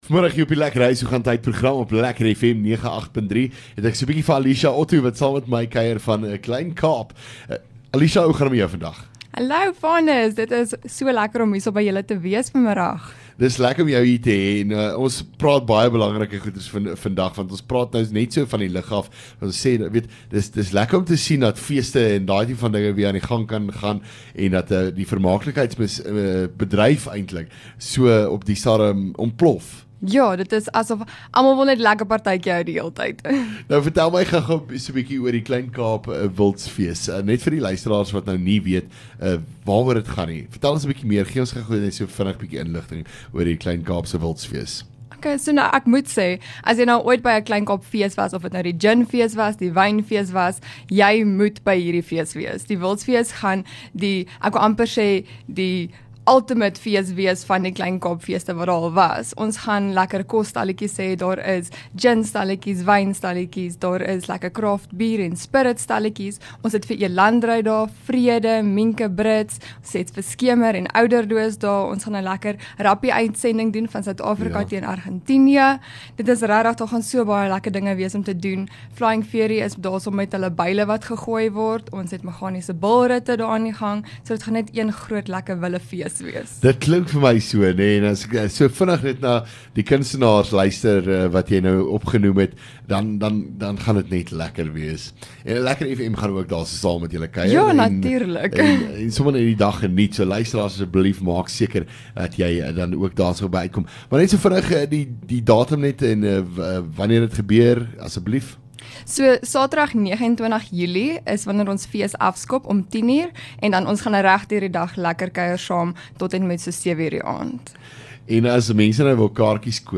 LEKKER FM | Onderhoude 12 Jul Kleinkaap Wildsfees